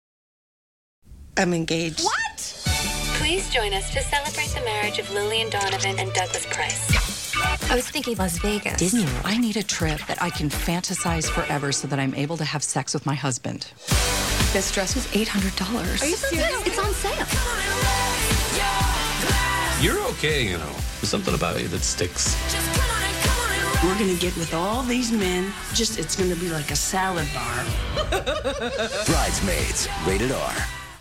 Brides Maids TV Spots